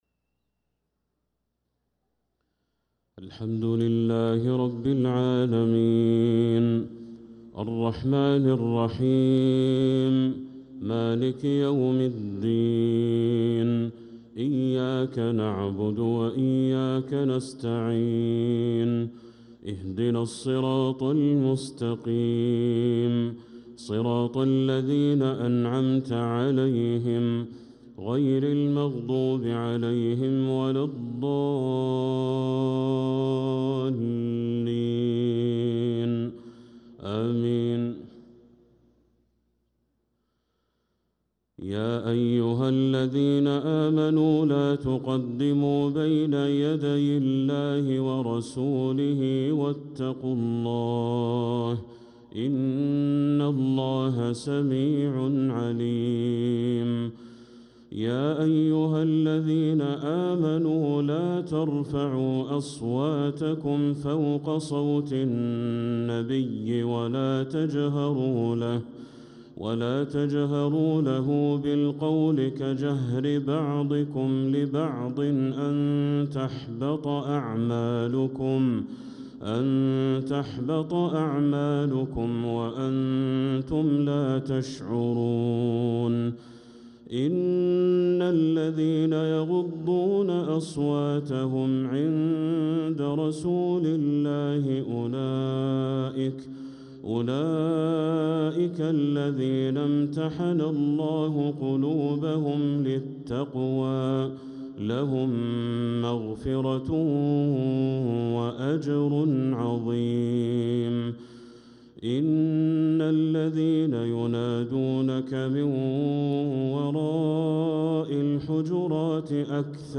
صلاة العشاء للقارئ بدر التركي 20 جمادي الأول 1446 هـ